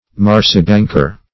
Search Result for " marsebanker" : The Collaborative International Dictionary of English v.0.48: Marshbanker \Marsh"bank`er\, Marsebanker \Marse"bank`er\, n. (Zool.)